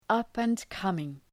Shkrimi fonetik {,ʌpən’kʌmıŋ}